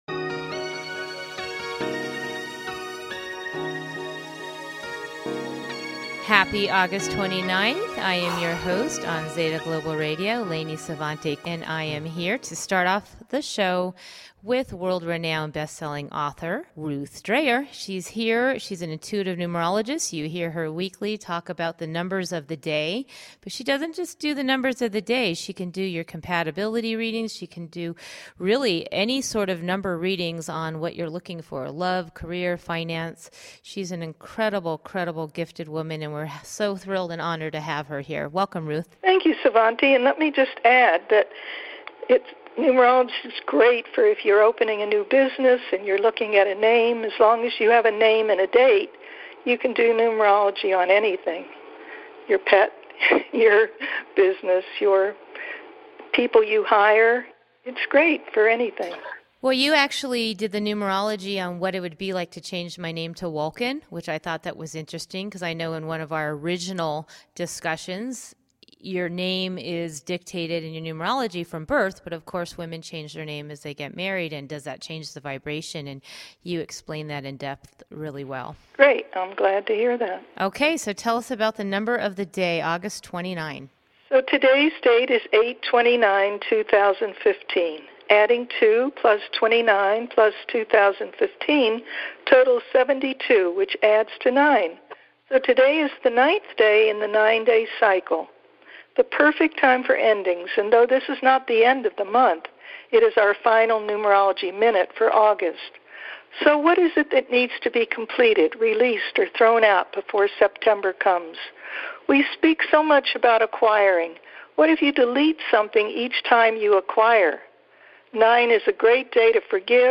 Talk Show Episode, Audio Podcast, Zeta Global Radio and with Conscious Music II on , show guests , about Conscious Music II,Conscious Music, categorized as Arts,Health & Lifestyle,Music,Philosophy,Psychology,Self Help,Spiritual,Variety